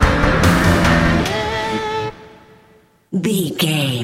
Thriller
Aeolian/Minor
synthesiser
drum machine
electric guitar
ominous
dark
suspense
haunting
creepy